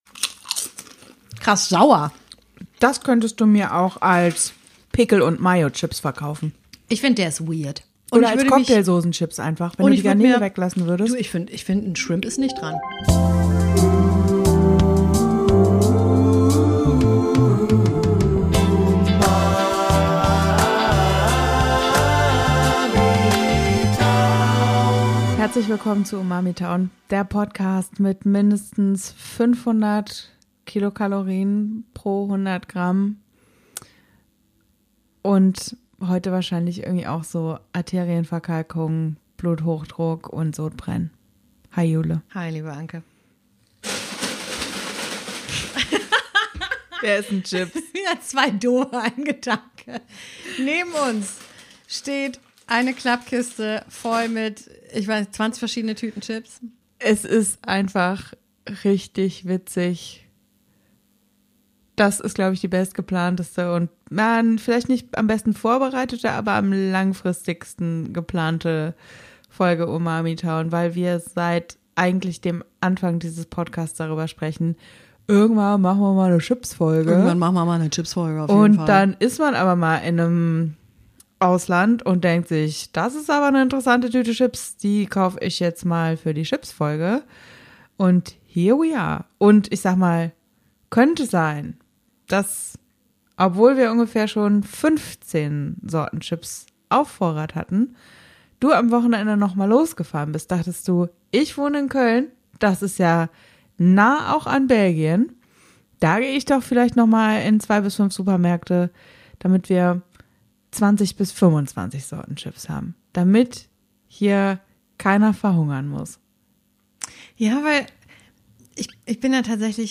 Egal ob geriffelt oder glatt, dünn oder dick, ohne oder mit Schale: Uns entkommt in Sachen Chips nichts und niemand. Es wird geraschelt und es werden Tüten aufgerissen, dass es eine reine Freude ist.